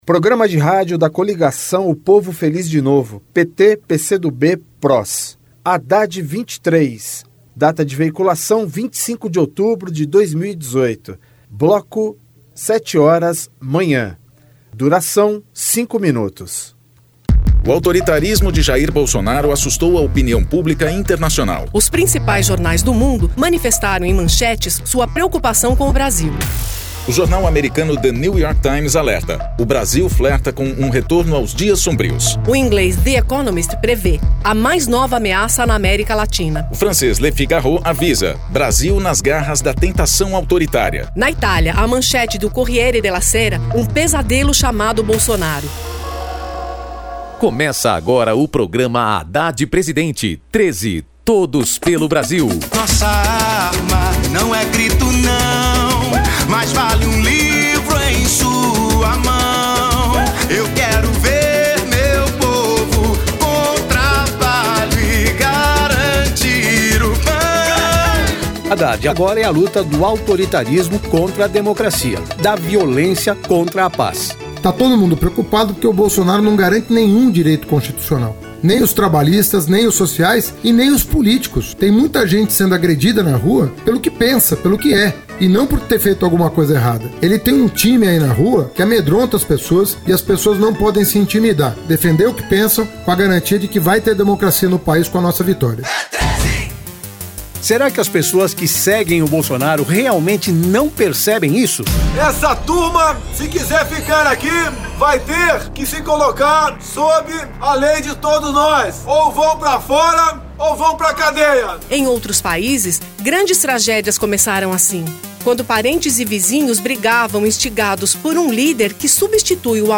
Gênero documentaldocumento sonoro
Descrição Programa de rádio da campanha de 2018 (edição 53), 2º Turno, 25/10/2018, bloco 7hrs.